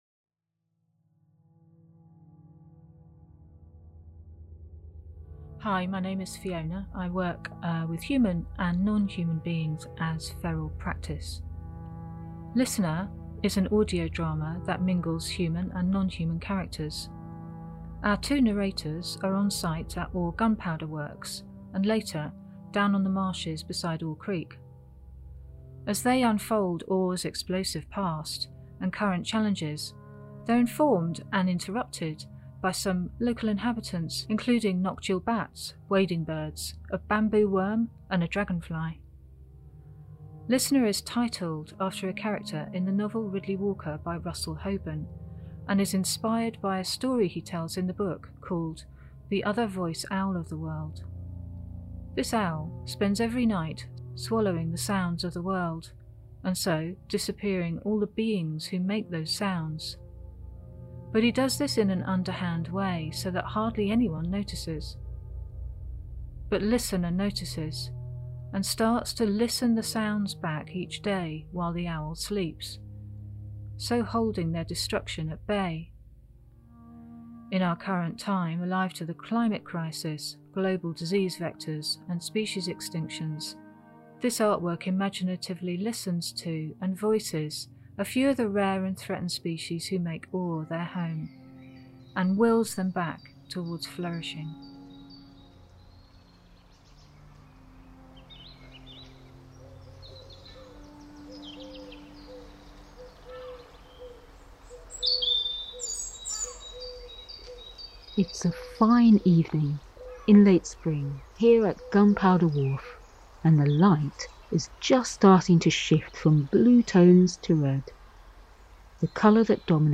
Lissener is a darkly comic audio drama that draws out the ecology and history of the Gunpowder Works, Creek and Marshes at Oare, on the North Kent coast. As two human narrators unfold Oare’s explosive past and current challenges, their dialogue is informed and interrupted by local creaturely characters who offer their perspective on events, including noctule bats, wading birds, a bamboo worm and a southern hawker dragonfly.
Words and stories are inserted into broader rhythms.
Alongside narrative, the artists use alternative field recording technologies such as hydrophones and contact microphones to access nonhuman kinds of vibrational experience.